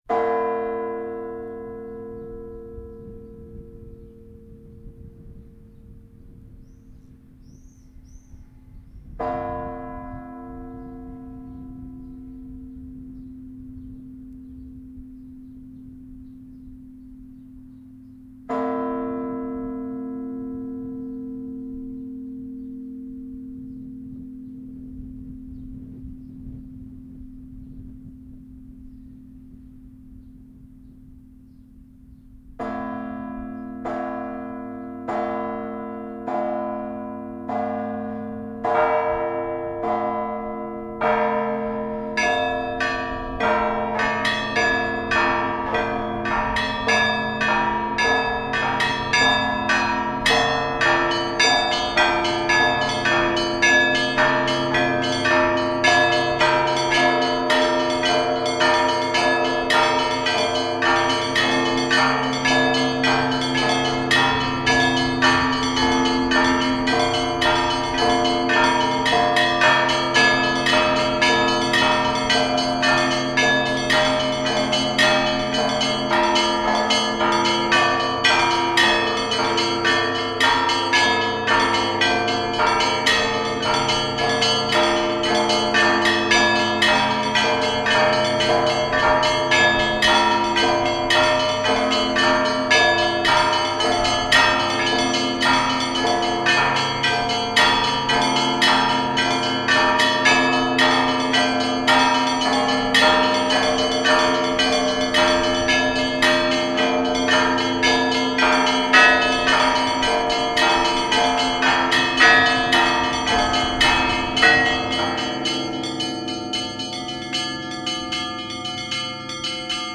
Иллюстрация к статье "Рождество Христово". Рождественский колокольный звон